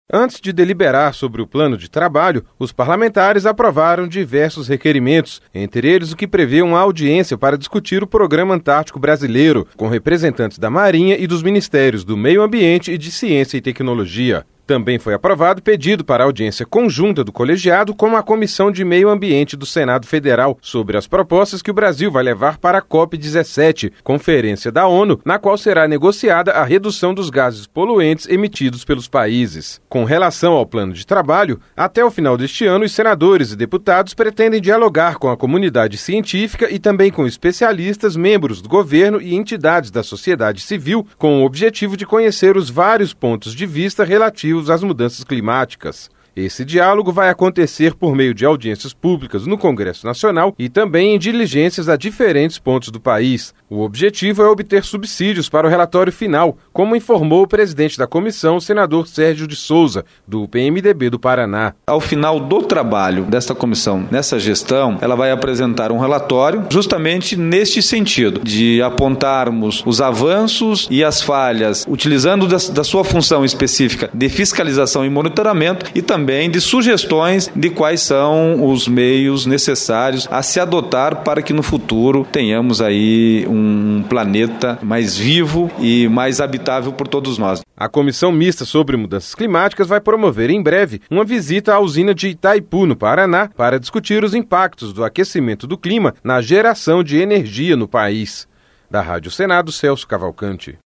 O objetivo é obter subsídios para o relatório final, como informou o presidente da Comissão, senador Sérgio de Souza, do PMDB do Paraná.